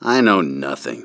―Timmy refuses further conversation with Katarn — (audio)
Any attempt by Katarn to further communicate with the bartender was met with impatient dismissal.[1]
NarShad_Bartender_Knows_Nothing.ogg